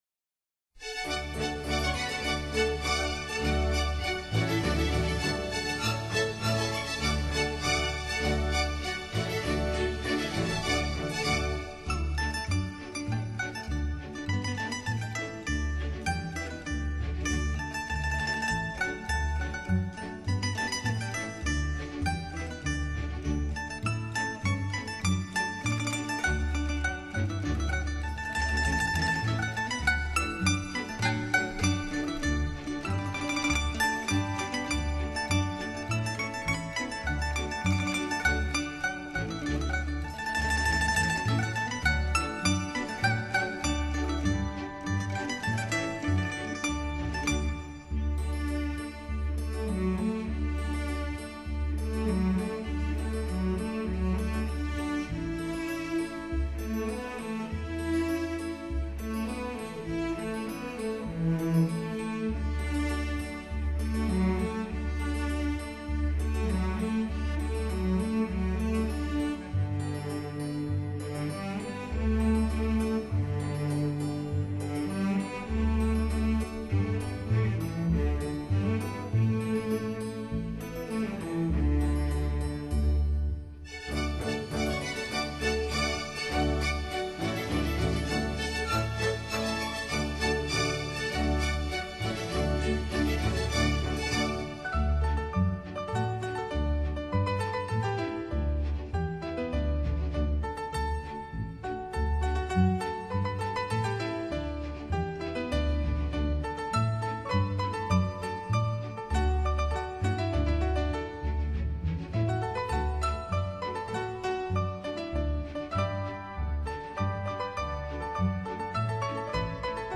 用New Age 等现代音乐手法再现中国民族音乐的精粹，在唱片界已不是什么新鲜的创意。
其音色的通透性之好，音场的无边拓展和三维空间感之强烈都为普通数码录音难以企及。